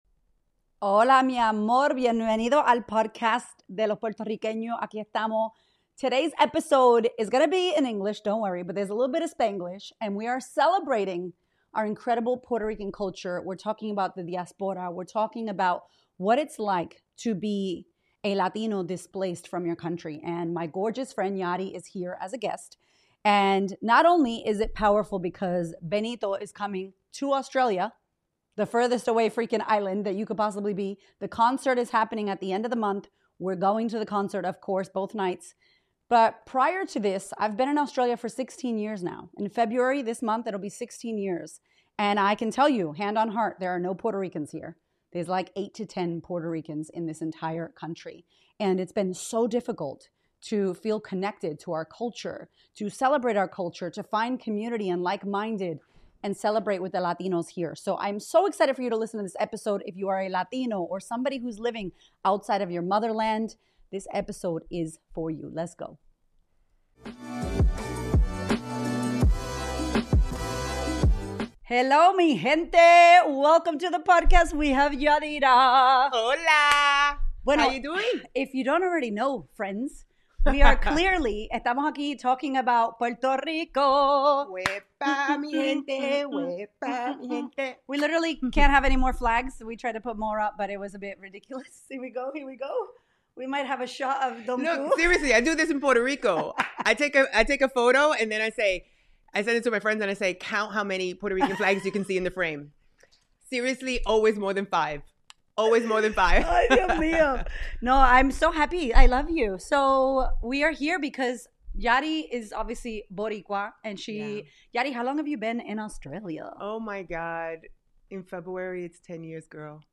This conversation was inspired by Bad Bunny bringing his global tour to Australia, selling out two massive shows, and reminding us how deeply culture lives in the body, especially when you’re far from home. We talk about diaspora, displacement, identity, grief for the motherland, and the responsibility of keeping culture alive for our children when it’s not reinforced by the environment around us.